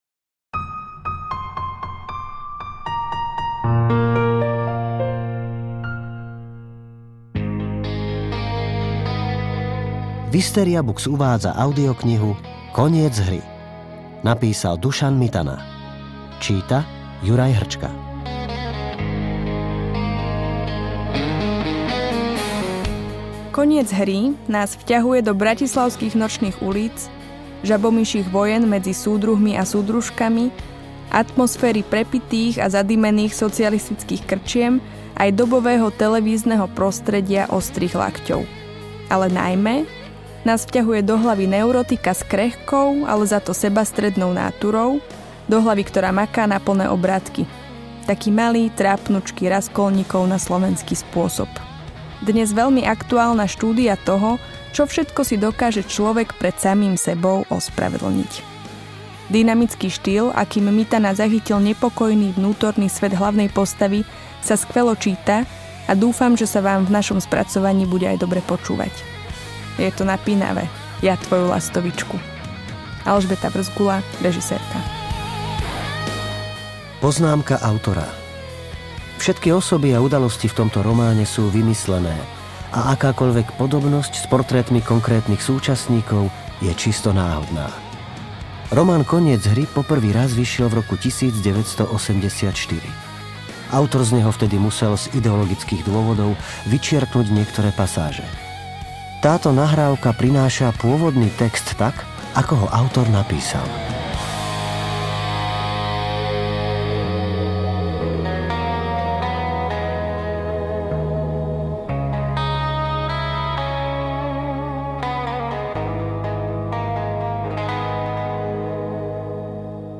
Audiokniha pre dospelých
Čita: Juraj Hrčka